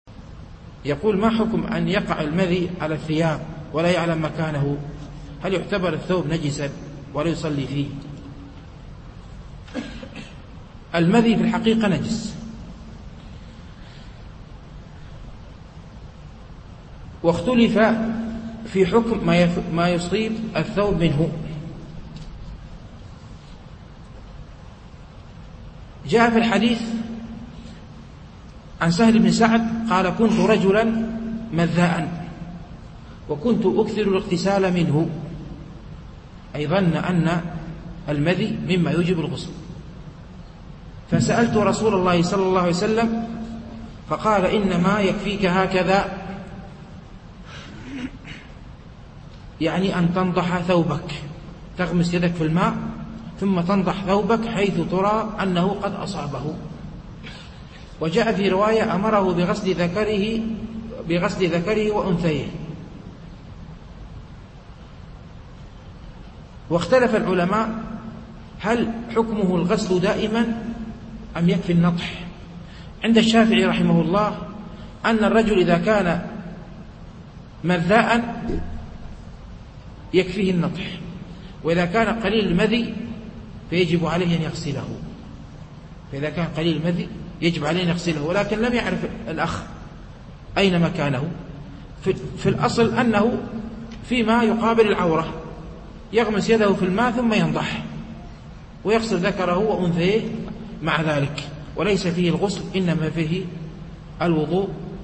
الألبوم: شبكة بينونة للعلوم الشرعية المدة: 1:45 دقائق (436.04 ك.بايت) التنسيق: MP3 Mono 22kHz 32Kbps (VBR)